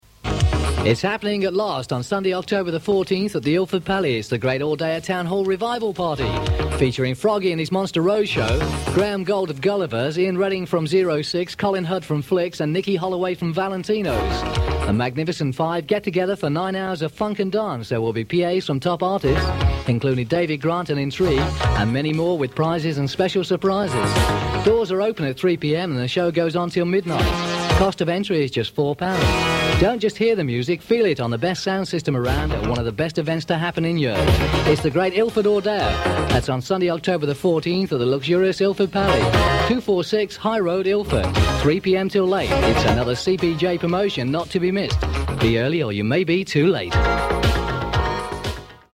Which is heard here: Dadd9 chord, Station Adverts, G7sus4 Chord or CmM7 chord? Station Adverts